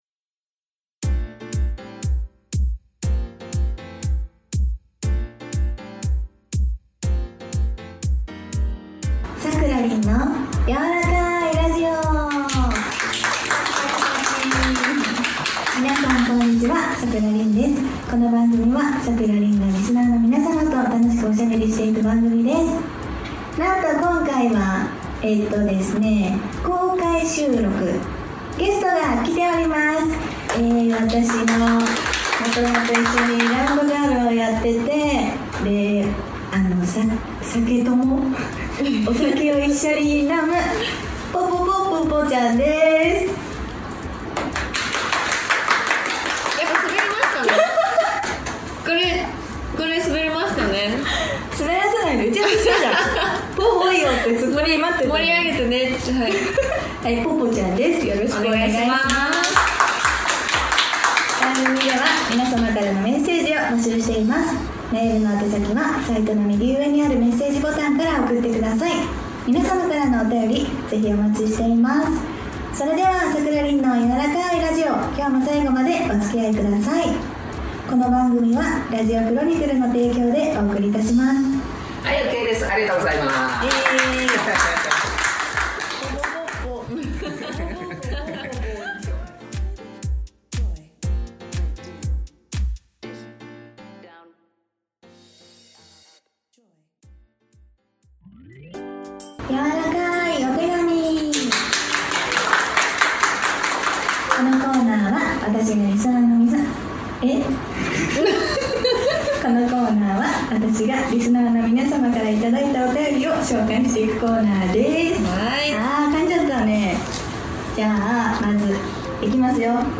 2回目の公開収録が開催されました！